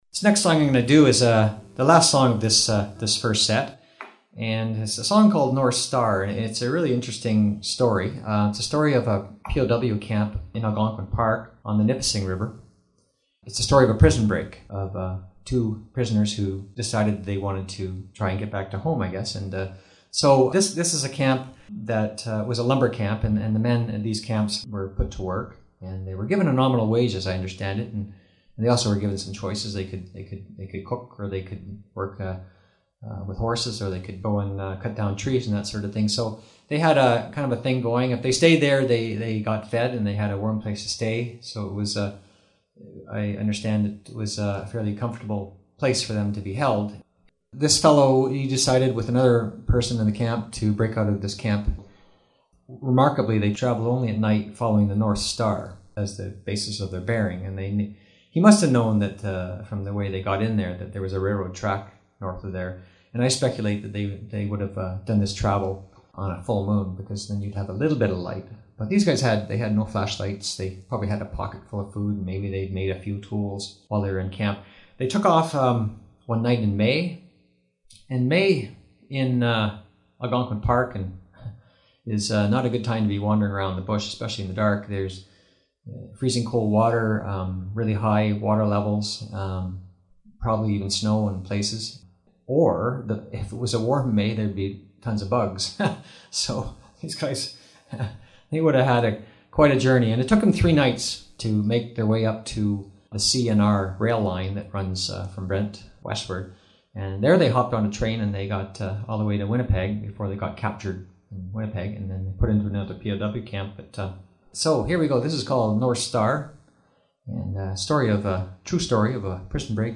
Recording Location: Bonnechere Valley, ON
story teller and singer